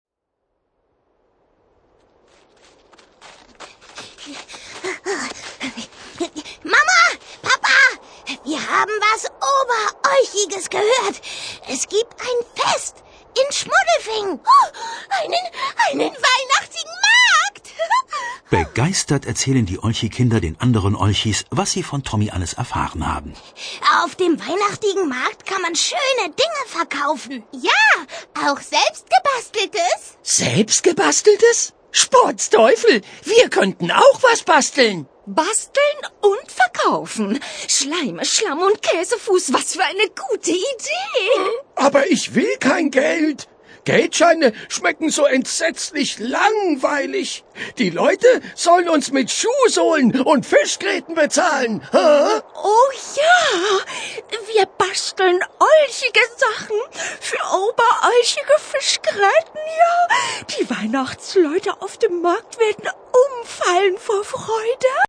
Schlagworte Audio-CD • AUDIO/Kinder- und Jugendbücher/Erstlesealter, Vorschulalter • Familie • Hörbuch für Kinder/Jugendliche • Hörbuch für Kinder/Jugendliche (Audio-CD) • Hörbuch; Lesung für Kinder/Jugendliche • Humor • Kinder-CDs (Audio) • Olchis • Phantastisches • Phantastisches / Utopie • Tonträger • Utopie • Weihnachten • Weihnachtsgeschichten; Kinder-/Jugendlit.